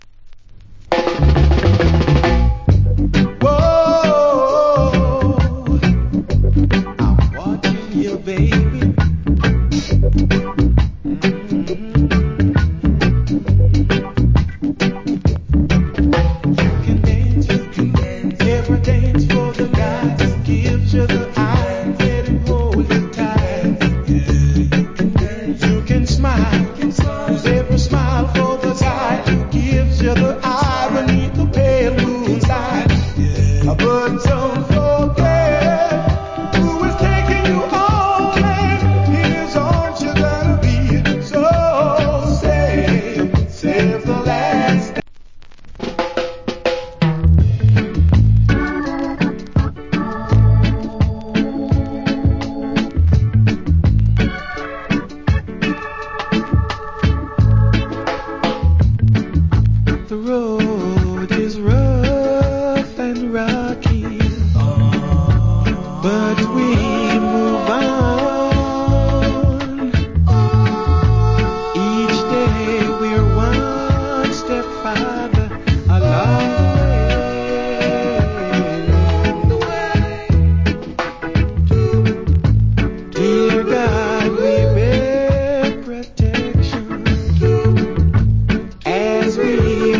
Good Reggae Voca.